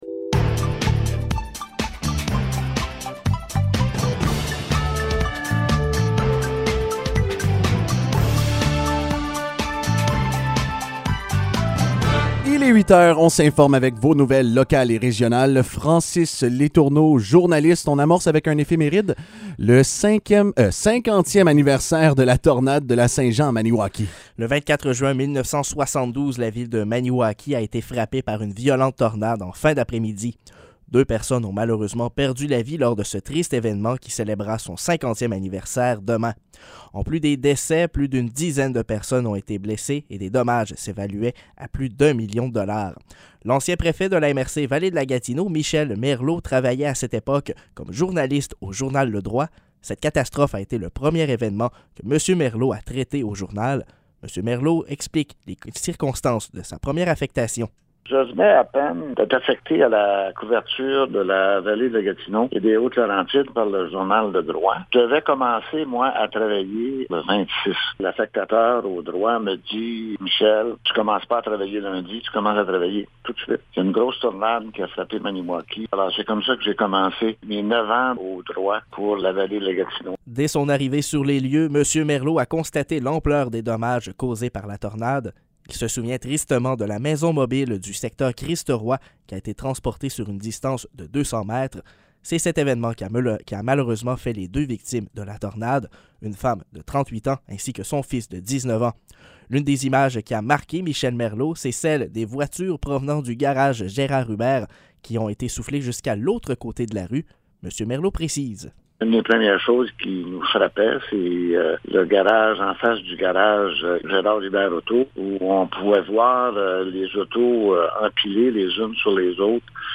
Nouvelles locales - 23 juin 2022 - 8 h